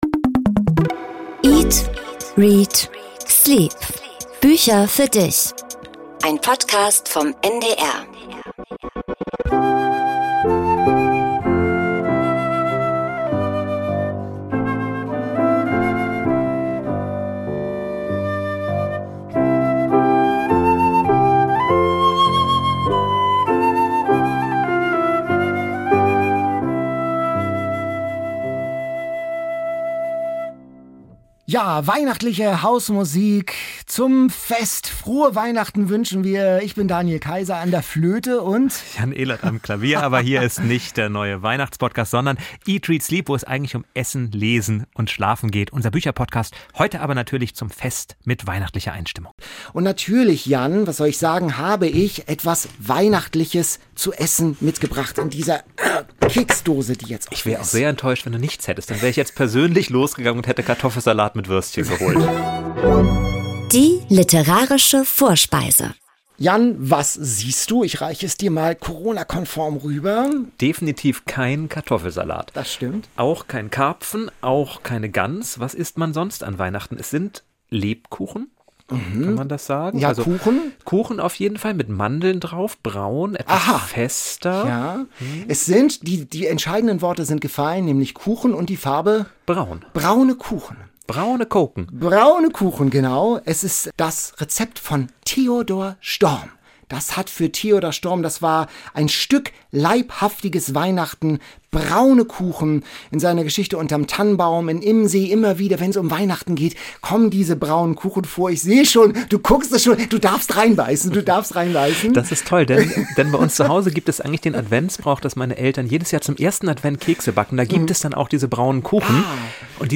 Hausmusik und Weihnachtsgedichte treffen auf grausame Krimisatiren. Und Gast Ildikó von Kürthy öffnet ihre Tagebücher. Weihnachtsstimmung im Podcast-Studio